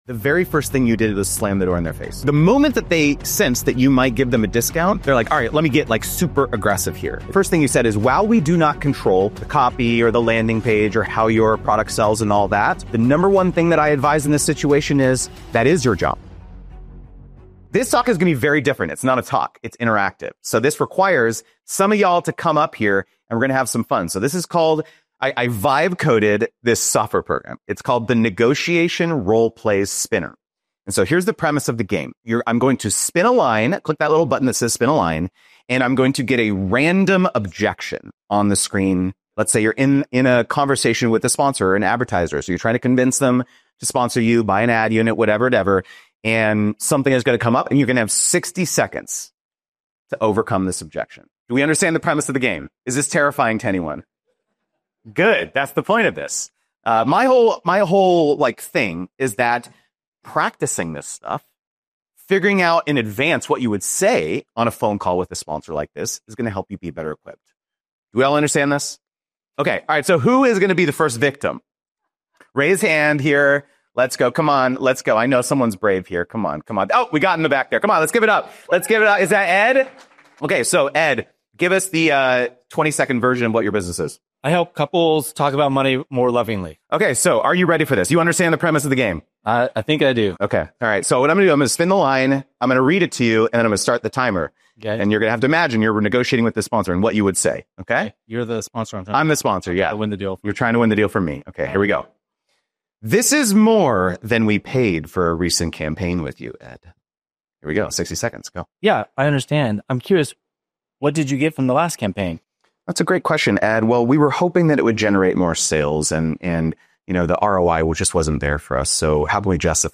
Watch Me Negotiate Live On Stage
I built a live negotiation simulator and put creators in the hot seat. Watch them tackle real objections—discount demands, equity offers, ROI concerns, event requirements - with 60 seconds on the clock.